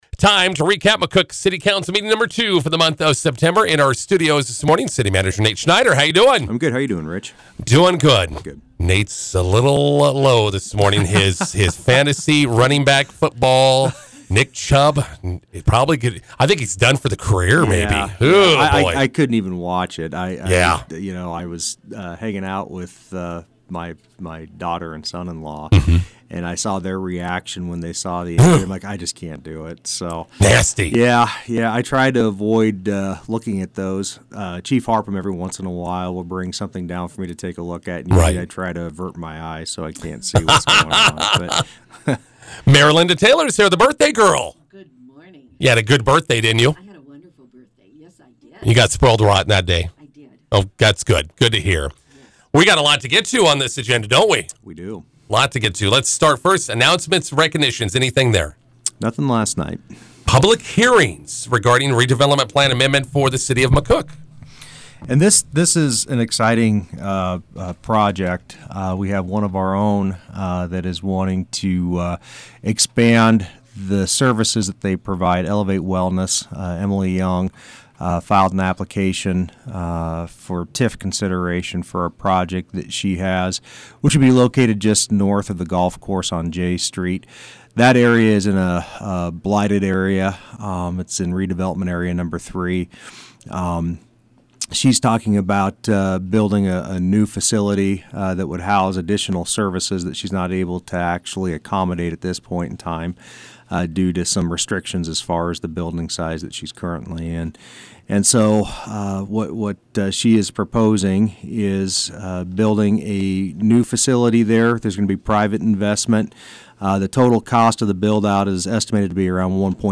INTERVIEW: McCook City Council meeting recap with City Manager Nate Schneider and Mayor Linda Taylor.